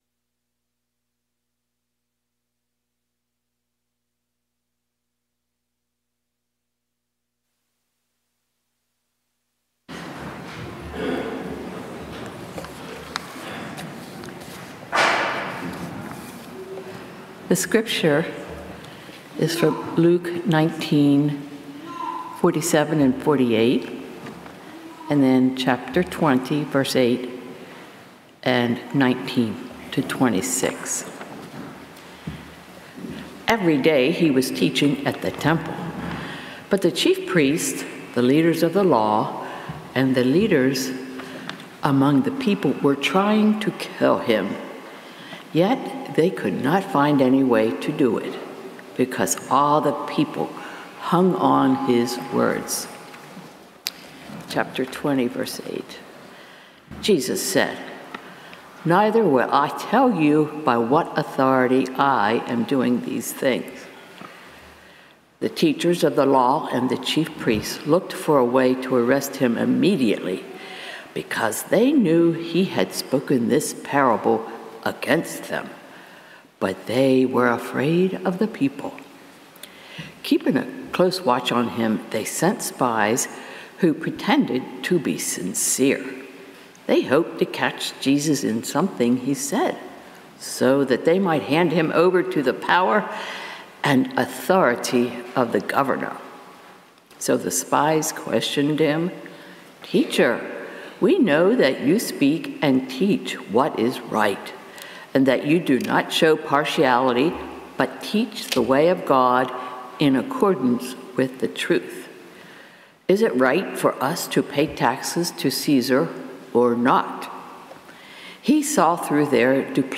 Sermons | Washington Community Fellowship